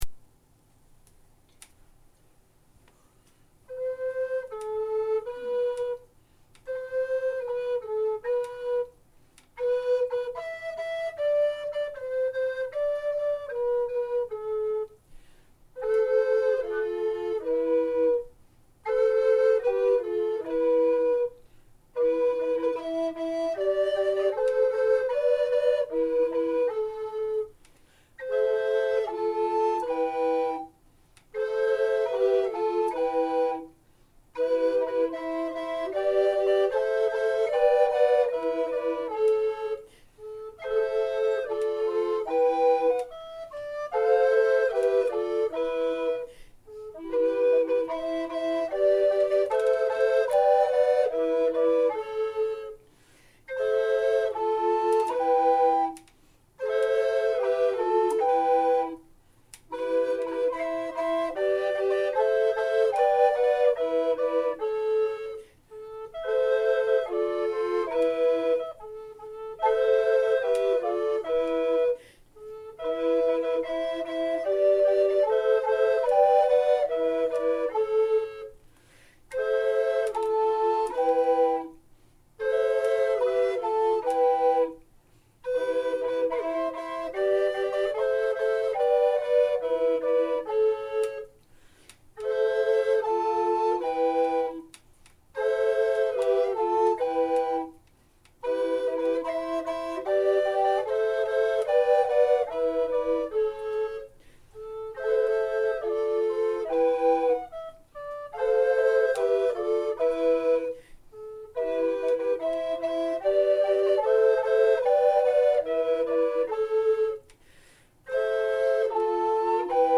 From our Renaissance repertoire